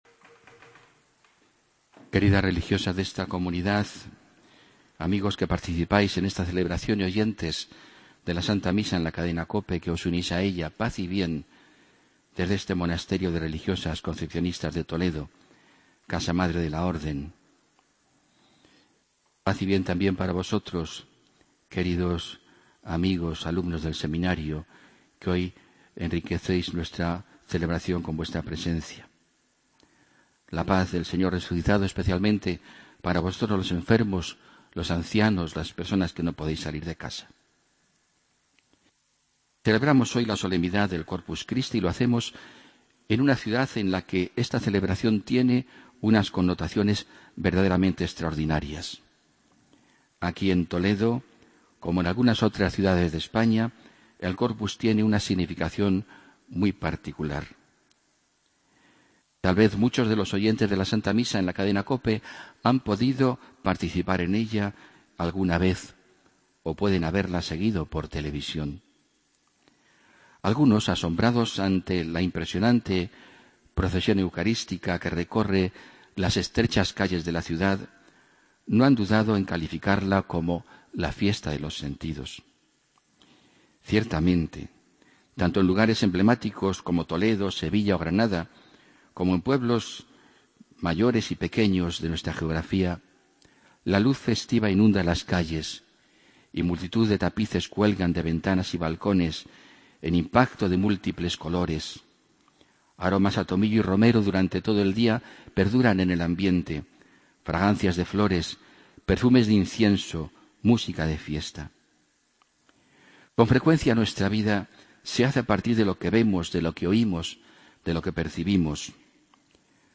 Homilía, domingo 6 de junio de 2015